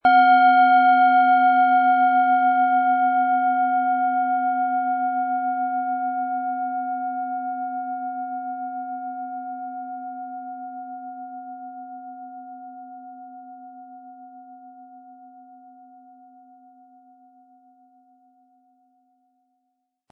Von Hand getriebene Schale mit dem Planetenton Sonne.
PlanetentöneSonne & Sonne
SchalenformBihar
MaterialBronze